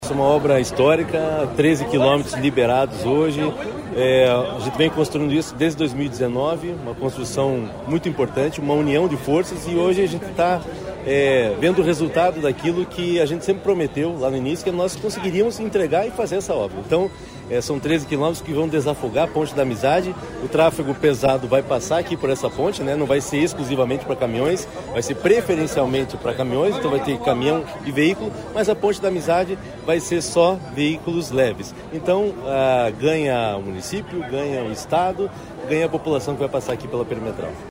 Sonora do diretor-presidente do DER, Fernando Furiatti, sobre a abertura da Perimetral Leste, em Foz